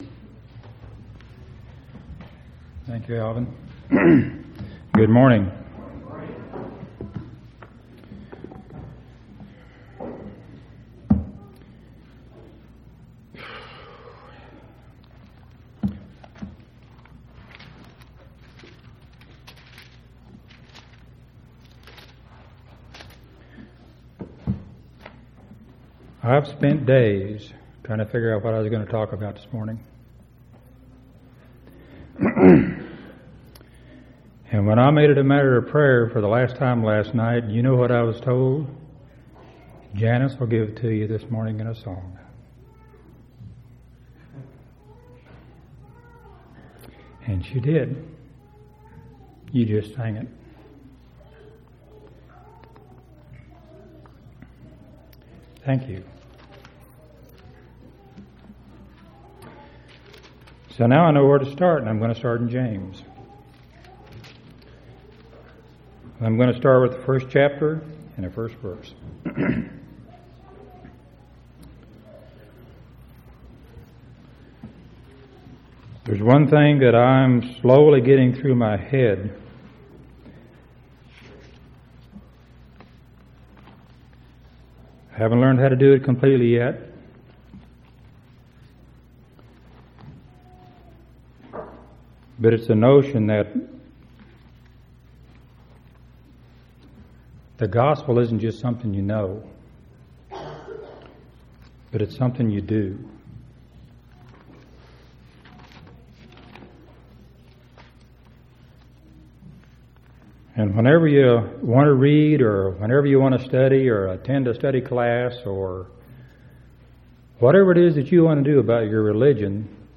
12/28/2003 Location: Temple Lot Local Event